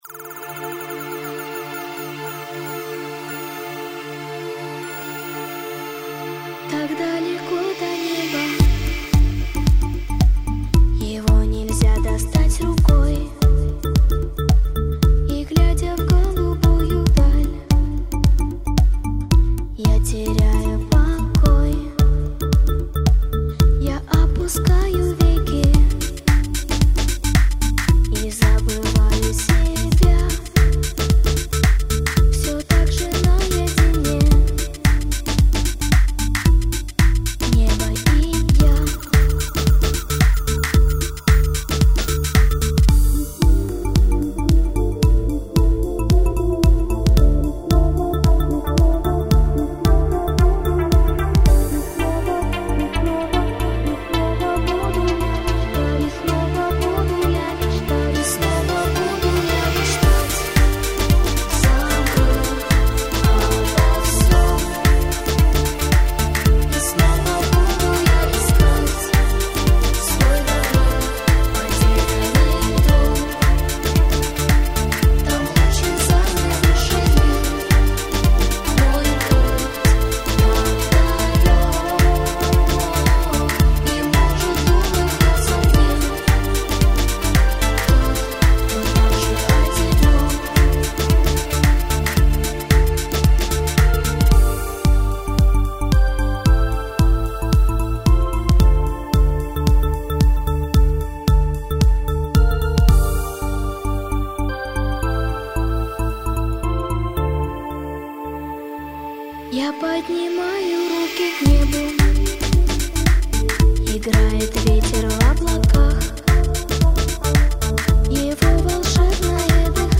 Жанр: Красивая музыка